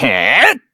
Kibera-Vox_Attack5_kr.wav